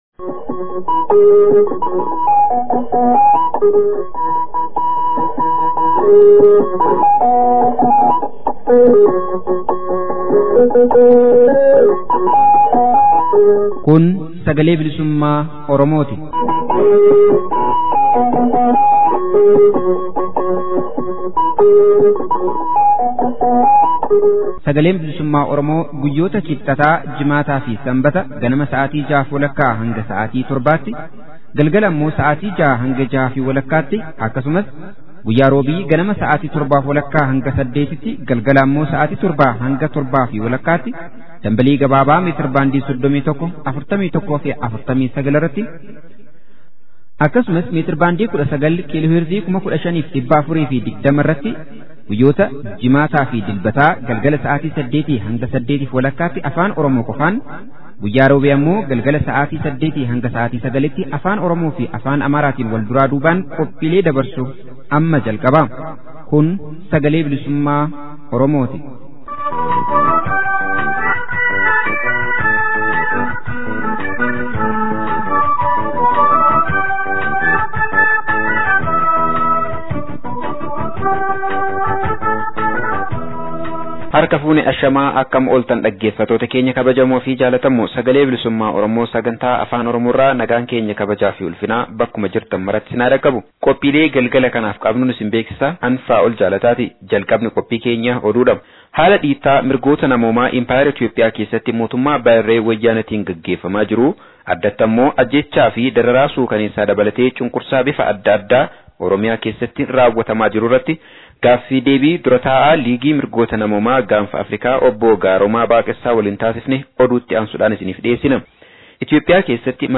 Oduu, Gaaffii fi deebii